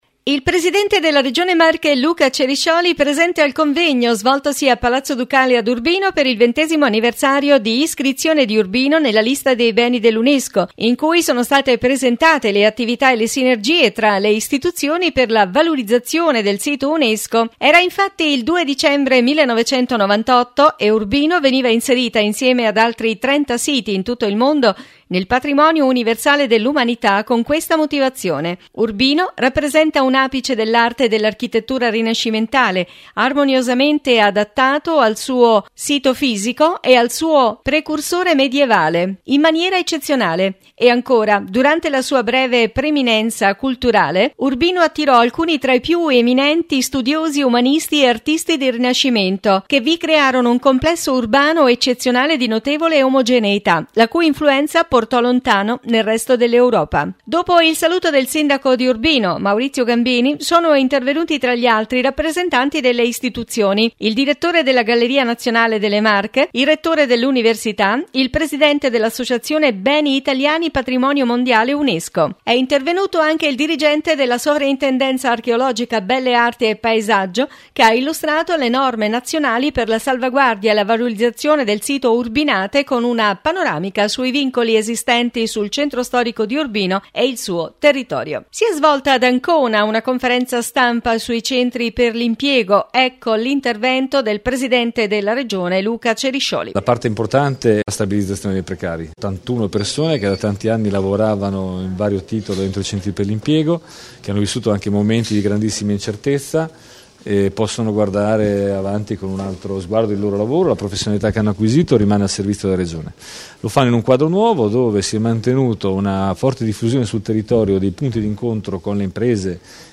….Trapianti, raggiunta “quota mille” cerimonia alla Mole di Ancona Intervista Luca Ceriscioli – Presidente Regione Marche